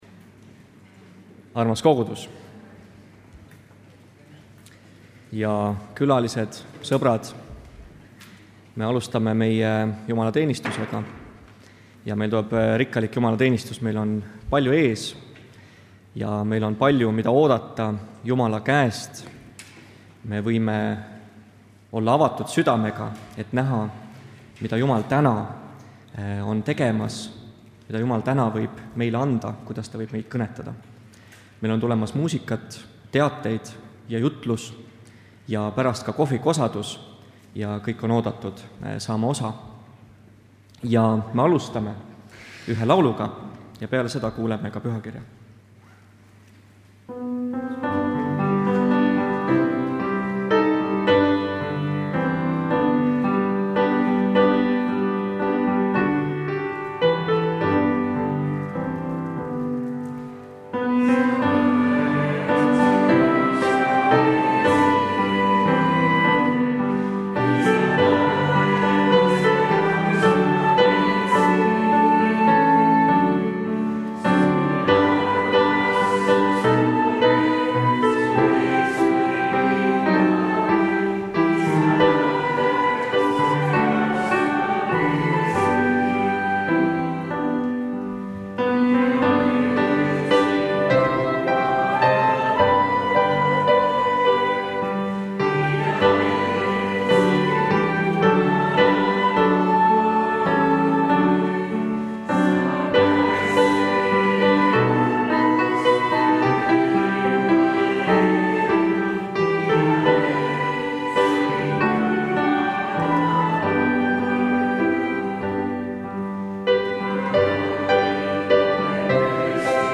Pühakirja lugemine ja palve
Jutlus